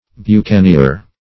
bucanier - definition of bucanier - synonyms, pronunciation, spelling from Free Dictionary
Buccaneer \Buc`ca*neer"\, n. [F. boucanier, fr. boucaner to
bucanier.mp3